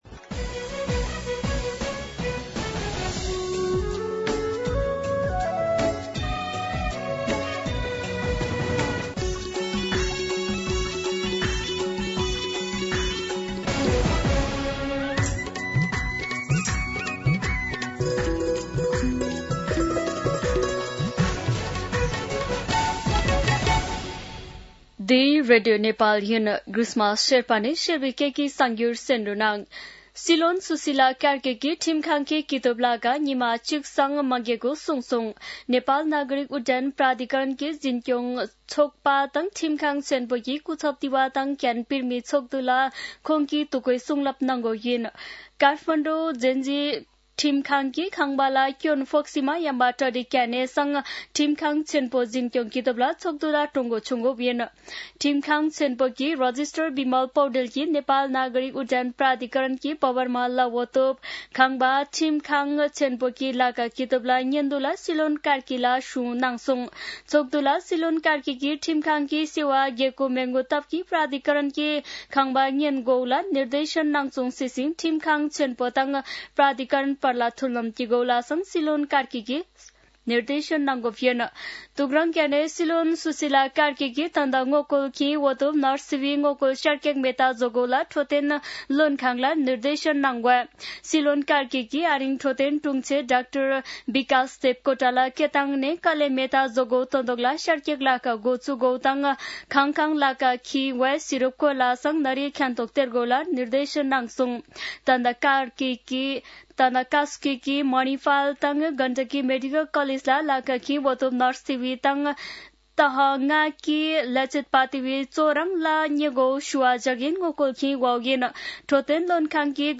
शेर्पा भाषाको समाचार : ३० असोज , २०८२
Sherpa-News-4.mp3